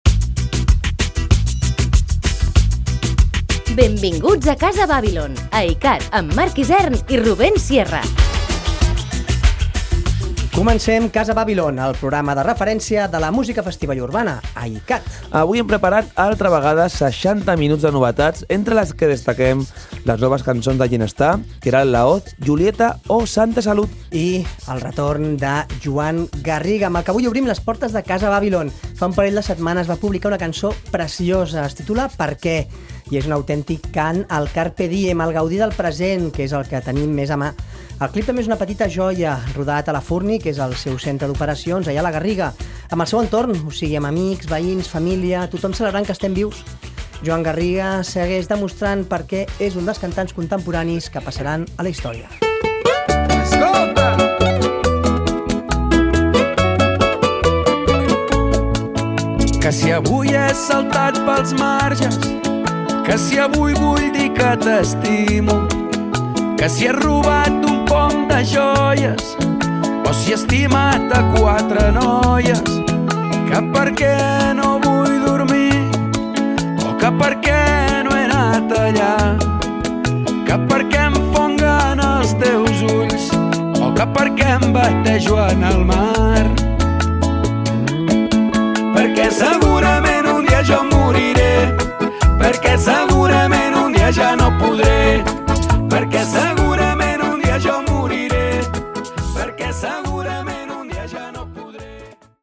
Careta del programa, novetats discogràfiques i nou tema de Joan Garriga.
Musical